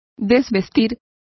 Complete with pronunciation of the translation of undresses.